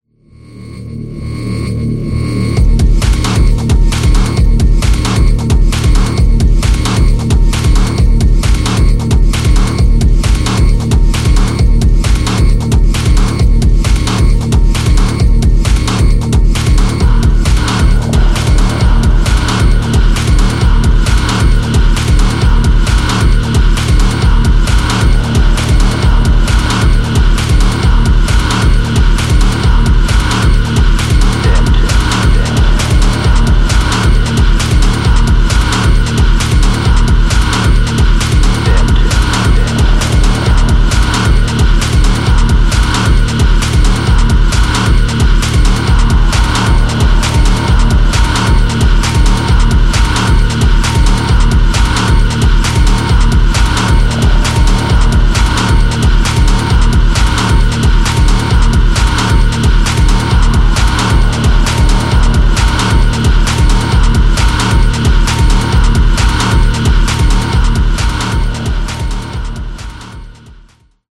Set in the canon of warm techno
is bright and unassuming